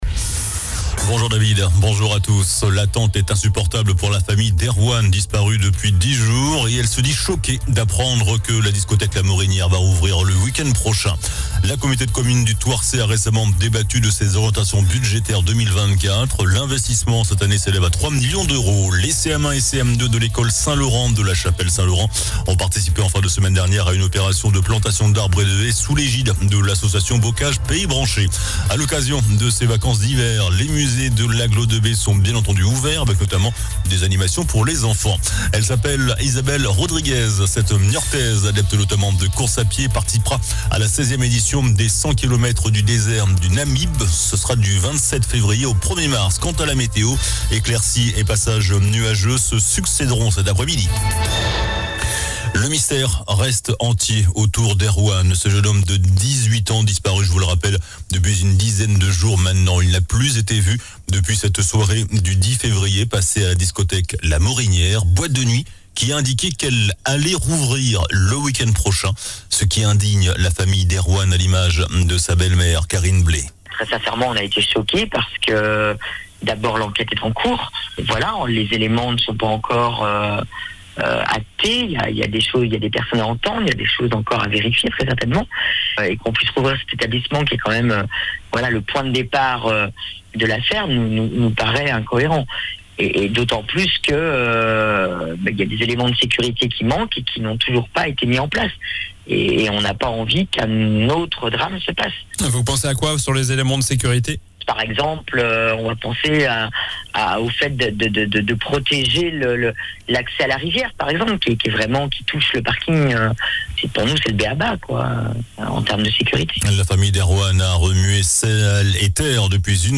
JOURNAL DU MARDI 20 FEVRIER ( MIDI )